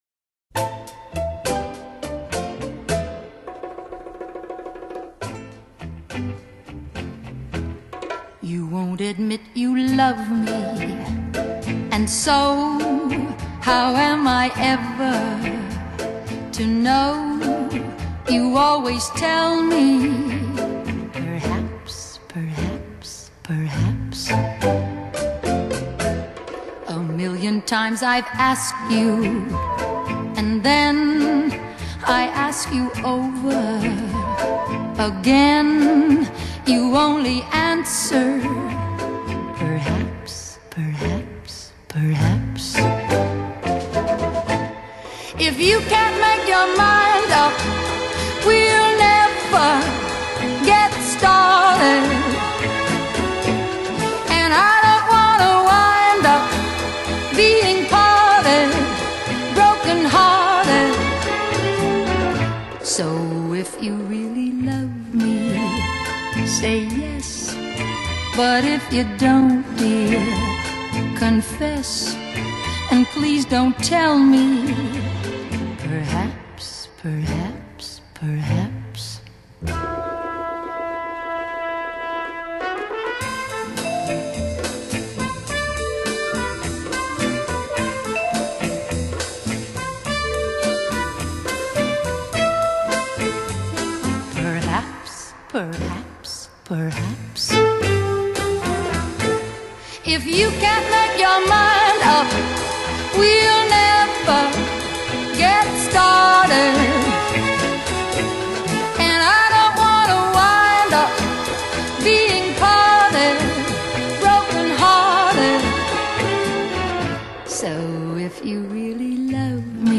Genre: Vocal Jazz, Pop, Easy Listening, Oldies